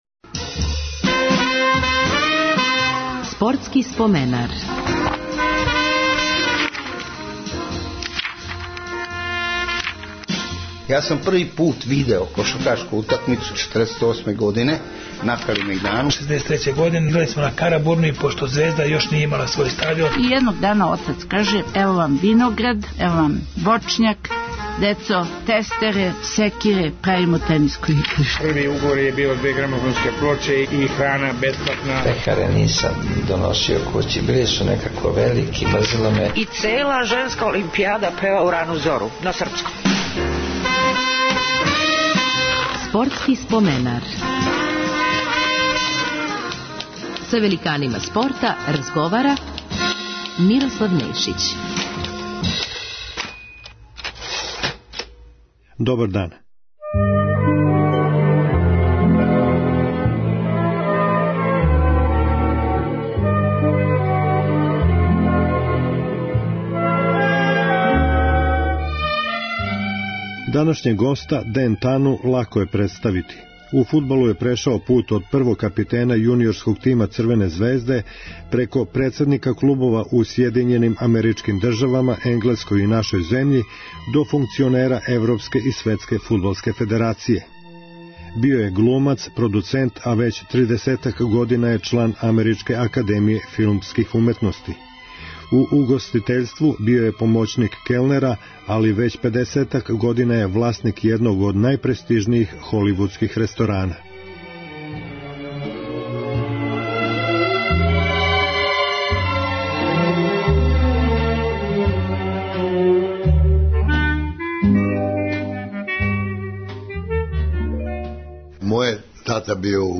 Гост 289. емисије је фудбалер и филмски продуцент Ден Тана. Био је капитен прве генерације омладинског тима „Црвене звезде“.